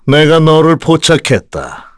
Crow-vox-awk_kr.wav